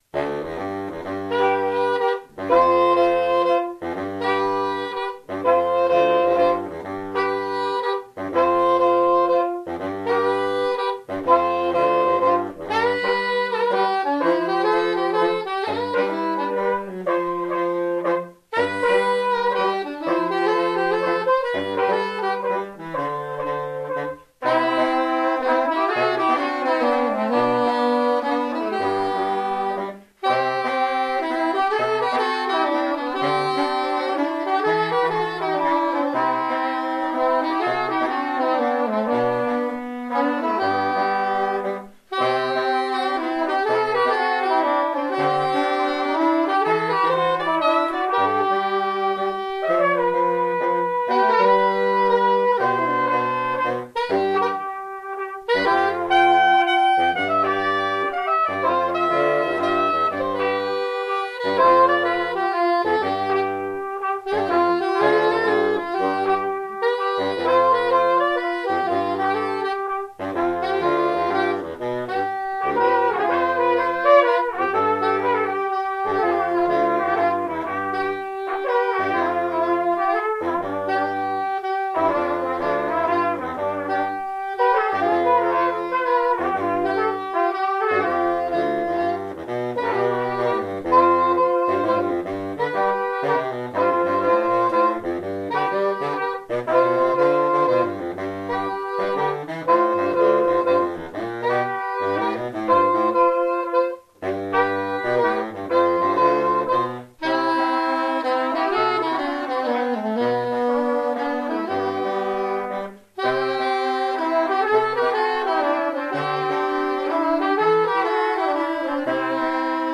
· Genre (Stil): Jazz